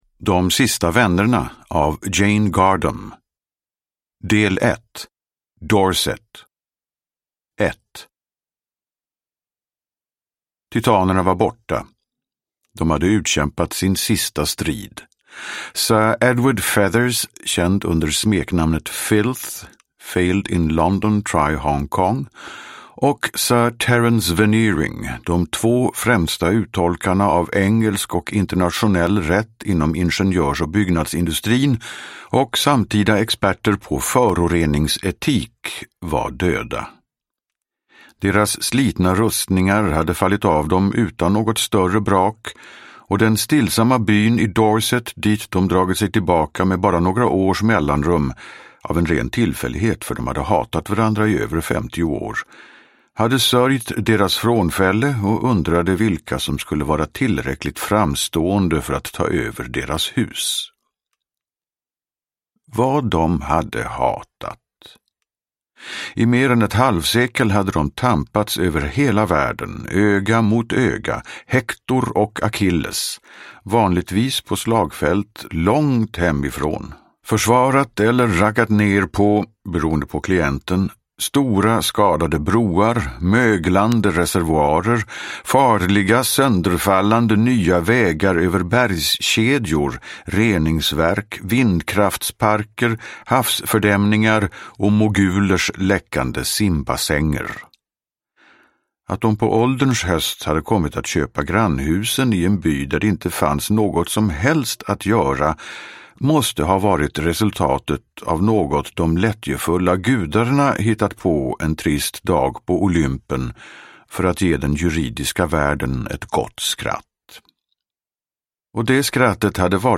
De sista vännerna – Ljudbok – Laddas ner